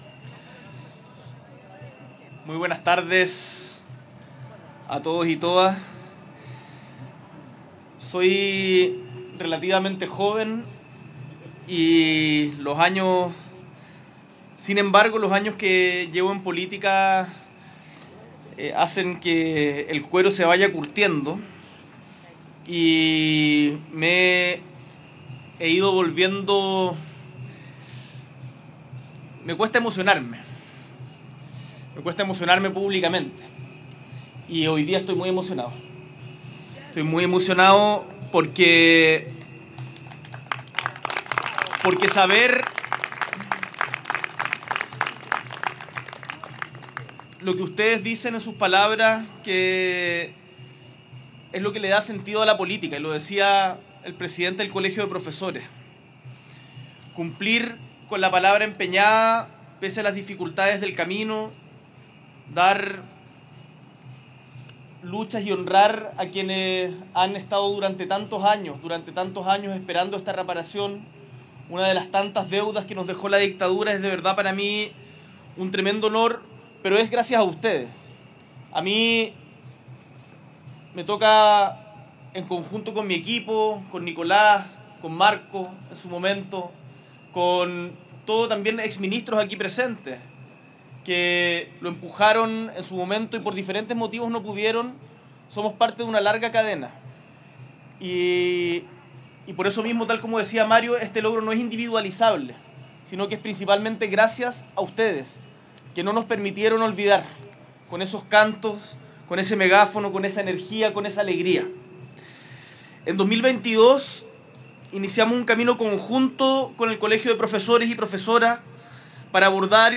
El Mandatario, junto al ministro de Educación Nicolás Cataldo; encabezó este lunes 2 de diciembre la ceremonia con la que el Gobierno concretó la presentación de una propuesta tangible para solucionar la problemática que afecta a miles de profesores y profesoras de Chile desde la dictadura.
La Plaza de la Constitución, específicamente el óvalo que durante años ha recibido al grupo de profesoras y profesores que demandaban una solución para una problemática que surgió durante la dictadura, fue el escenario en que se concretó de manera oficial la primera propuesta de gobierno para reparar la deuda histórica del Estado con los docentes de Chile.